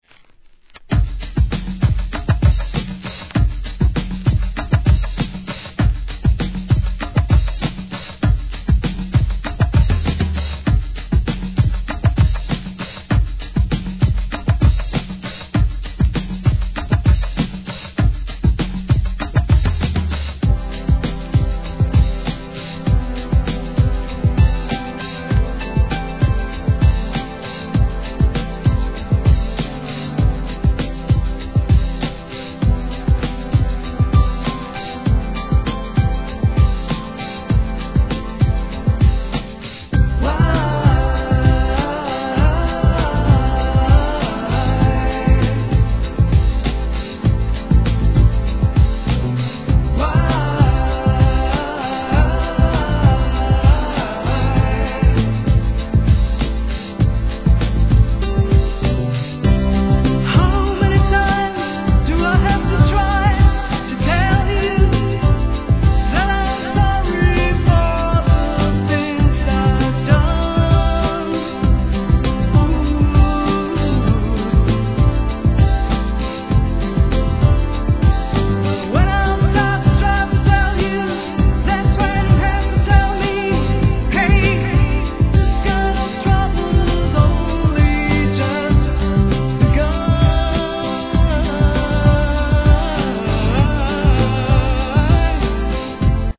HIP HOP/R&B
グランドビート特有の打ち込みに壮大なスケール感、なみだなくしては語れない余りにも切ないネロディーライン